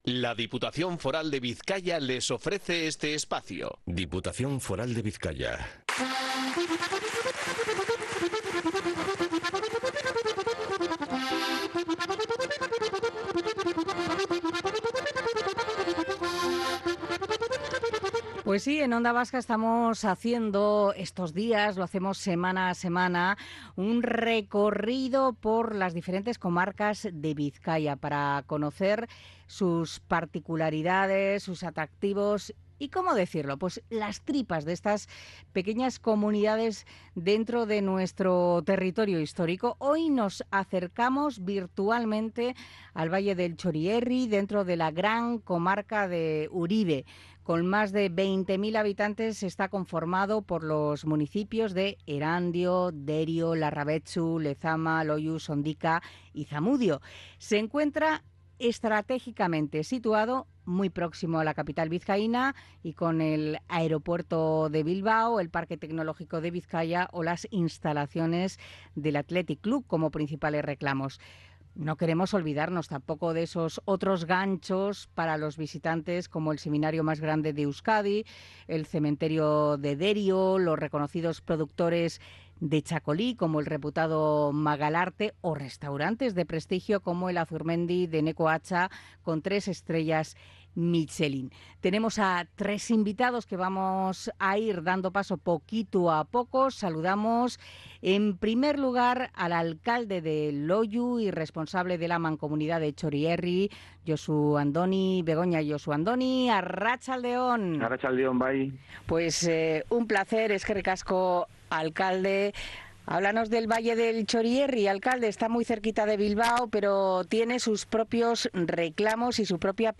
Nos acercamos al Valle del Txorierri en Bizkaia con el responsable de la Mancomunidad y alcalde de Loiu